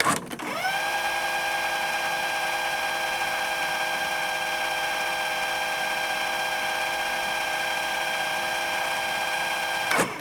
Casete avanzando rápido
cinta magnética avanzar bobinar casete